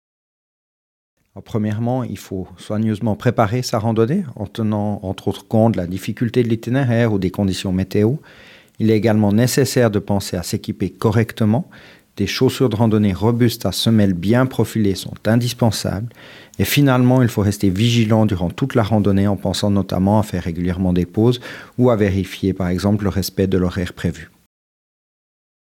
porte-parole